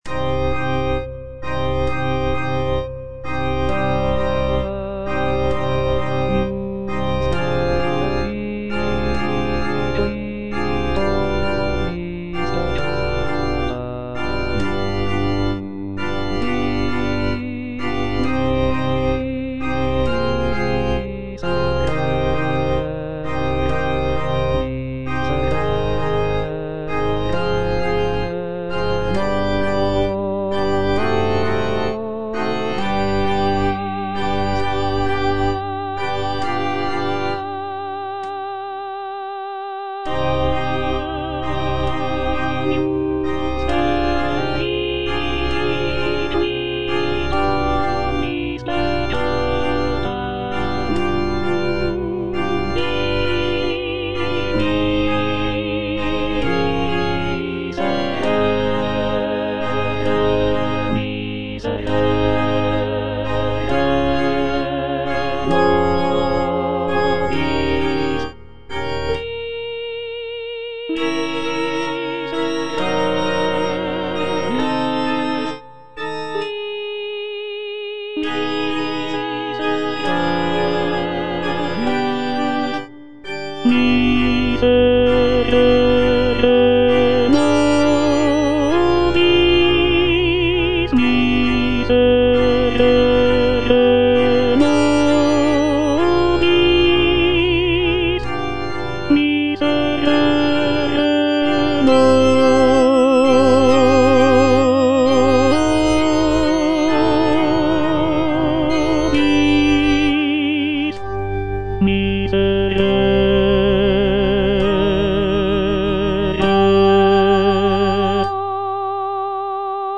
C.M. VON WEBER - MISSA SANCTA NO.1 Agnus Dei - Tenor (Voice with metronome) Ads stop: auto-stop
"Missa sancta no. 1" by Carl Maria von Weber is a sacred choral work composed in 1818.
The work features a grand and powerful sound, with rich harmonies and expressive melodies.